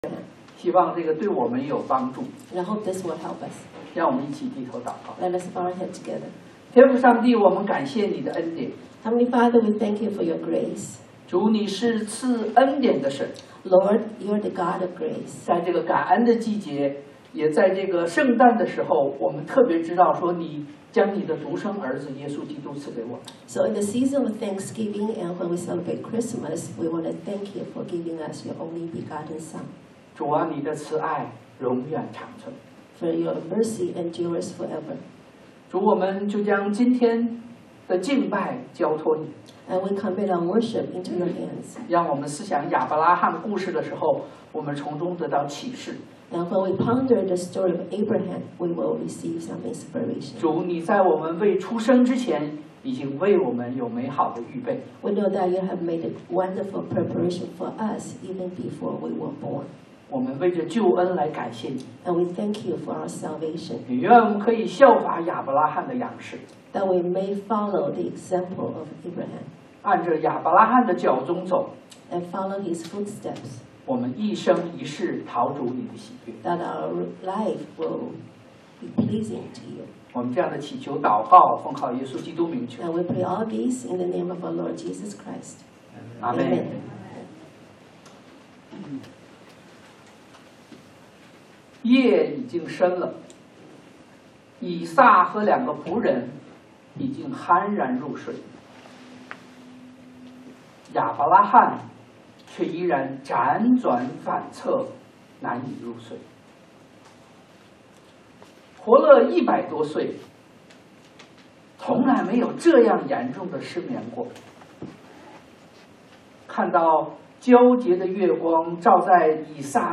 Sermon
創 Gen21:1-19 Service Type: Sunday AM 亞伯拉罕的不眠之夜 The Sleepless Night of Abraham 經文Passage